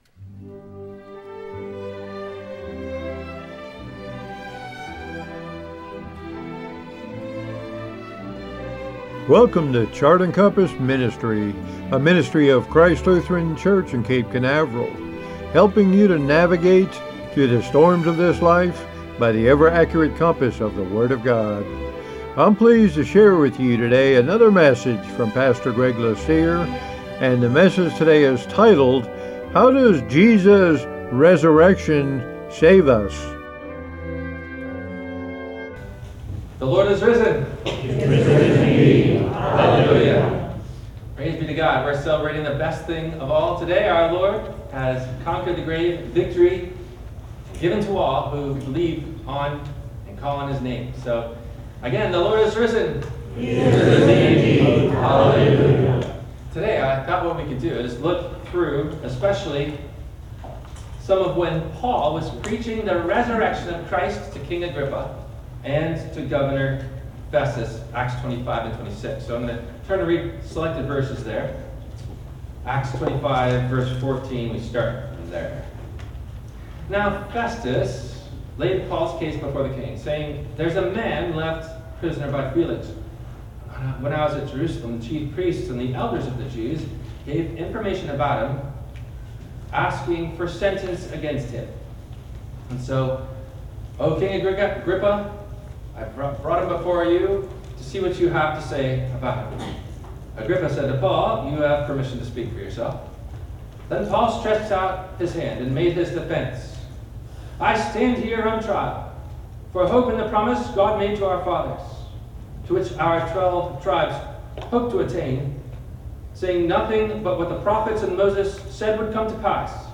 No Questions asked before the Sermon message: